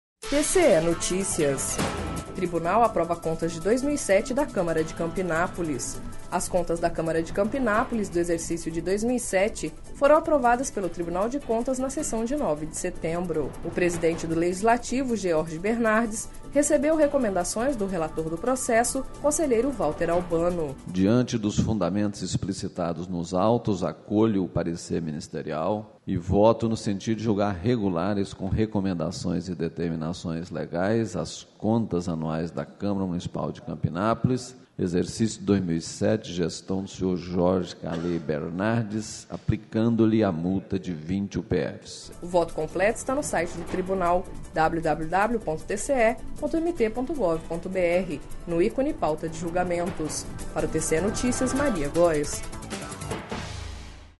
Sonora: Valter Albano– conselheiro do TCE-MT